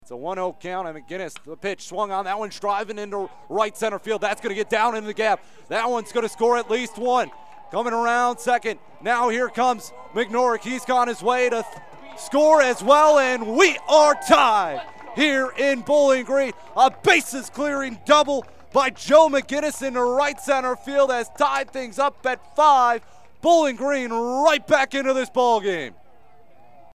RADIO CALL: